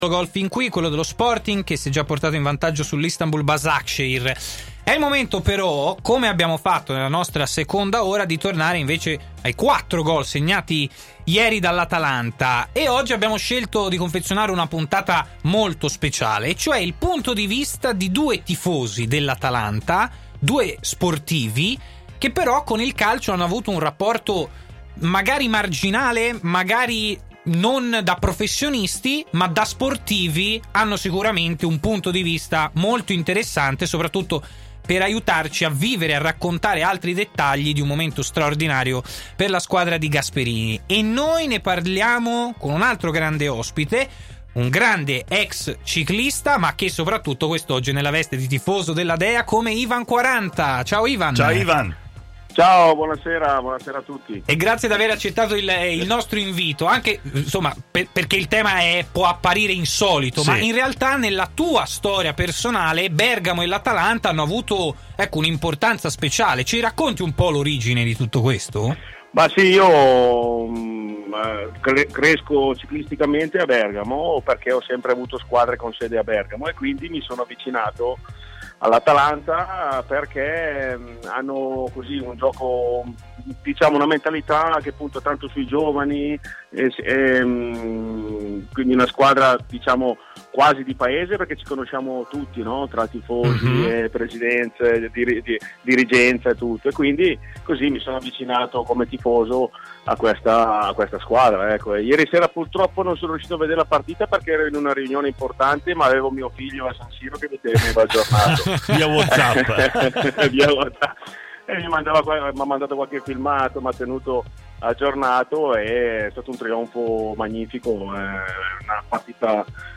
Ivan Quaranta, ex ciclista italiano e tifoso dell’Atalanta è intervenuto su TMW Radio durante Stadio Aperto.